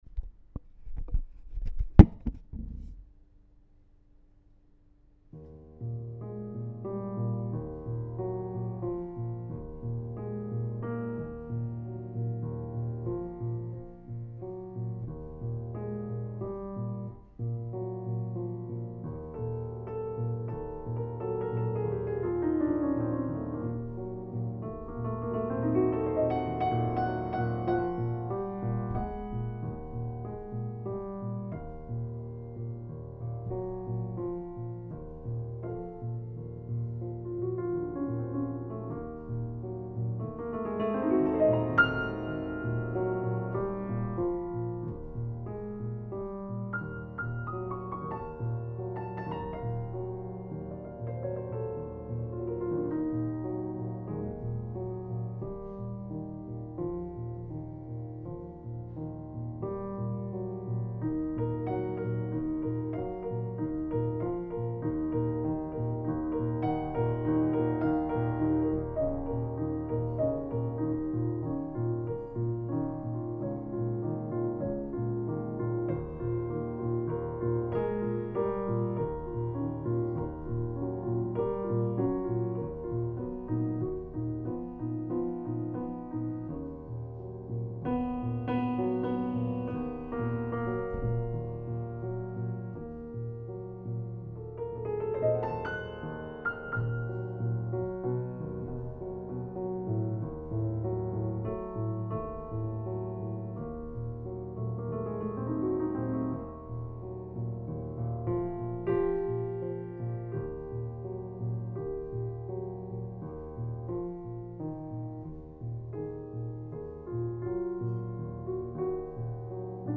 Nocturne
Piano Music